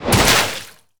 water_spell_impact_hit_03.wav